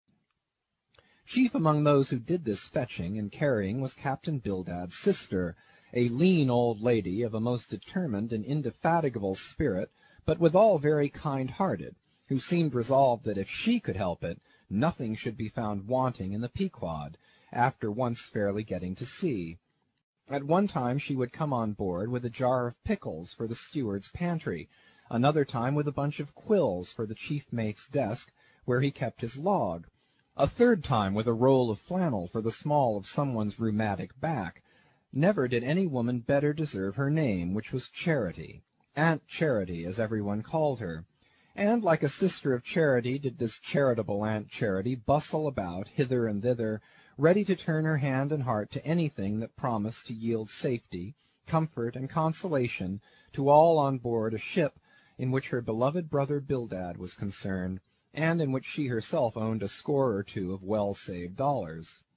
英语听书《白鲸记》第112期 听力文件下载—在线英语听力室